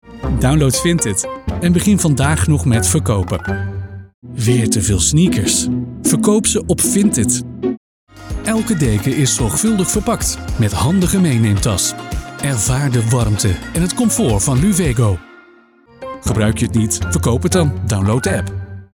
Voz
Seguro, Amable, Empresarial
Comercial